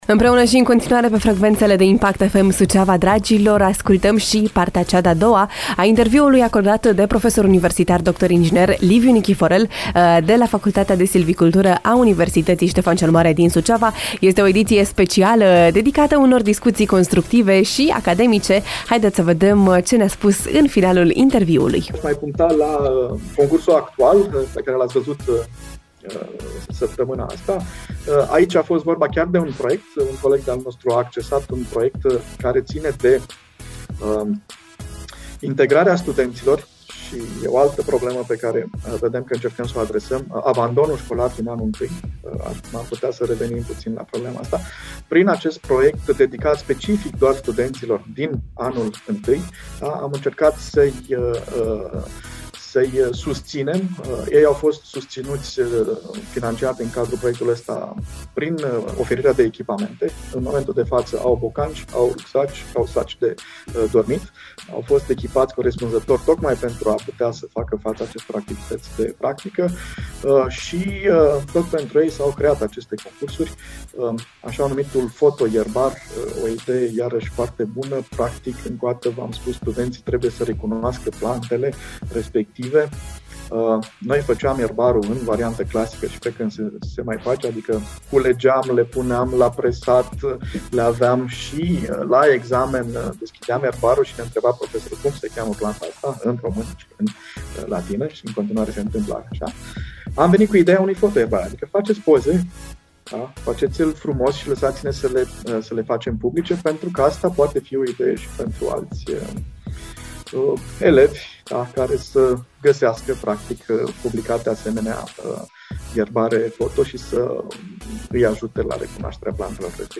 În cadrul interviurilor speciale dedicate Universității „Ștefan cel Mare” din Suceava